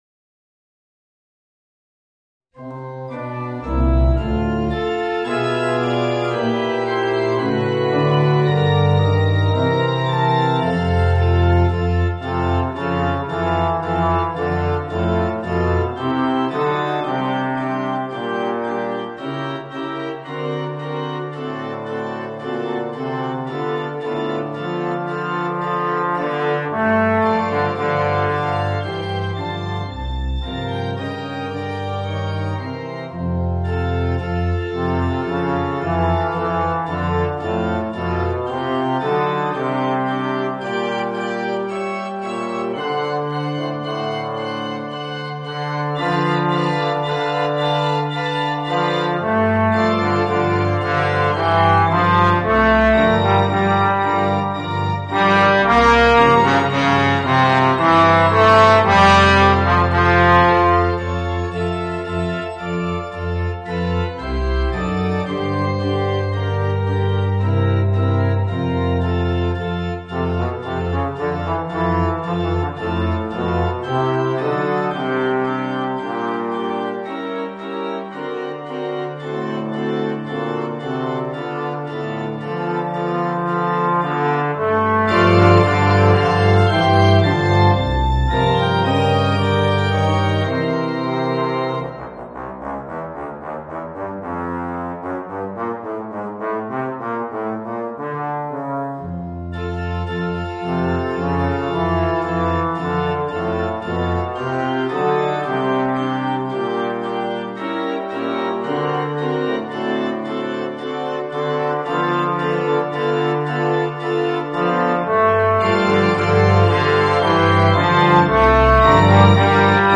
Voicing: Bass Trombone and Organ